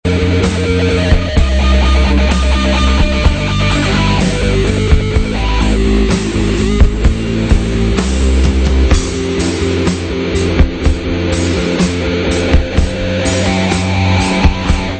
pop indie